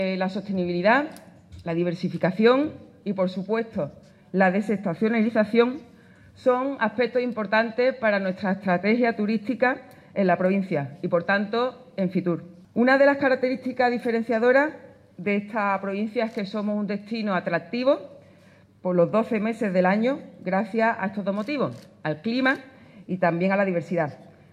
Esas son las claves de la estrategia de la Diputación de Cádiz para impulsar la industria turística en la provincia, según ha informado la presidenta de la Diputación, Almudena Martínez. Ha sido durante el acto de presentación a nivel regional de la oferta que Andalucía promocionará en la próxima Feria Internacional del Turismo, FITUR, que se celebrará en Madrid del 24 al 28 de enero. Un acto que ha tenido lugar en Jerez de la Frontera y que ha contado con las intervenciones del consejero de Turismo de la Junta de Andalucía, Arturo Bern